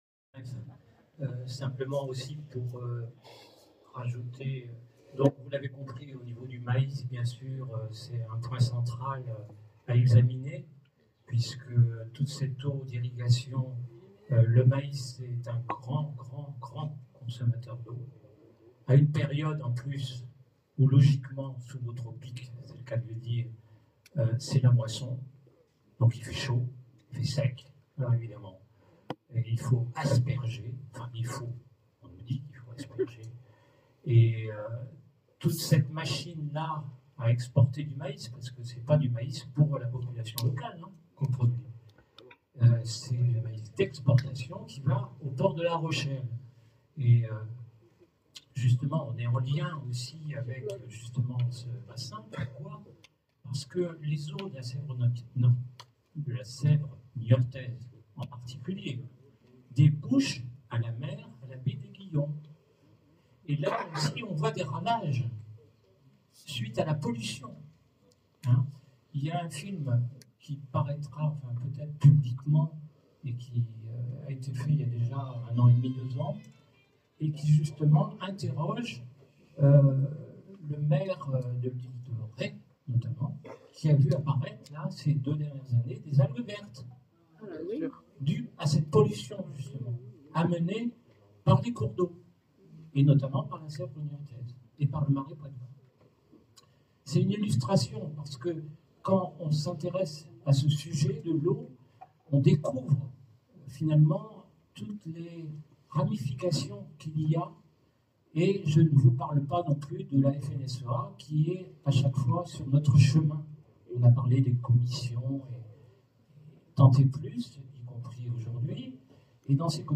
Conférence sur l’eau
AG Indecosa 25 oct 2021